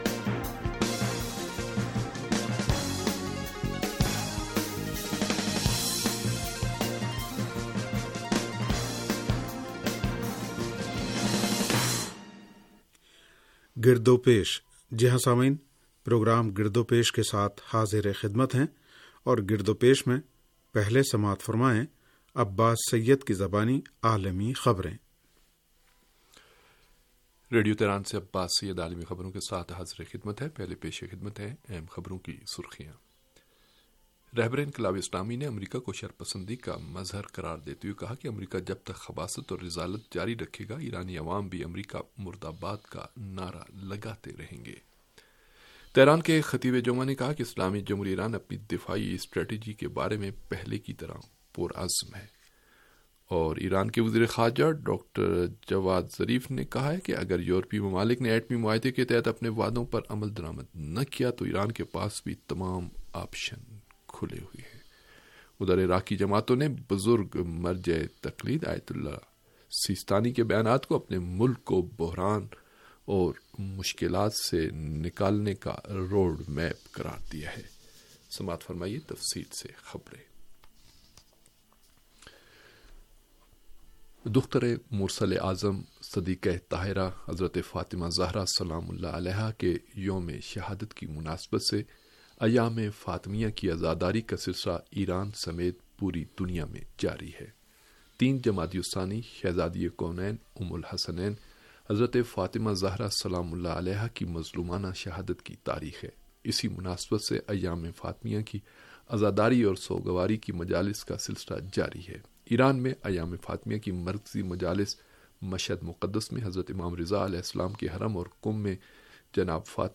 ریڈیو تہران کا سیاسی پروگرام - گرد و پیش